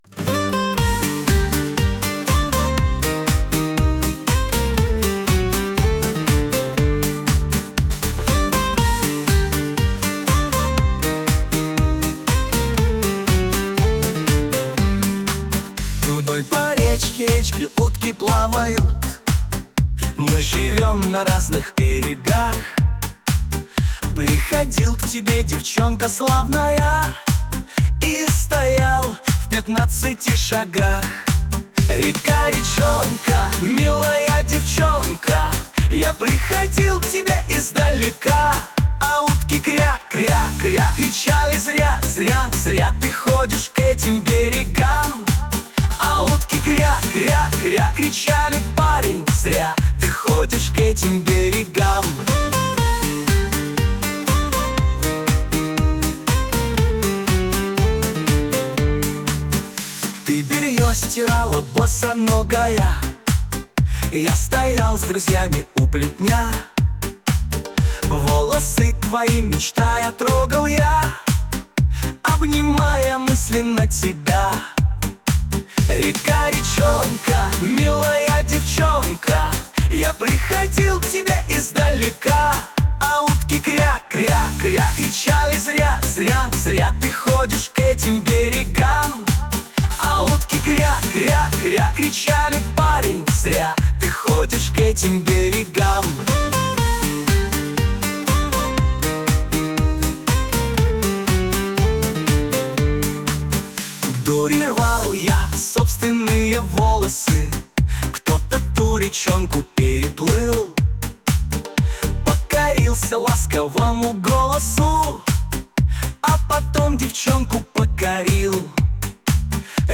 Уважаемые Завалинцы, я решил напомнить Вам (а кого-то познакомить) легендарную дворовую песню 1960-х.
Предлагаю вам два варианта, мелодия изменена.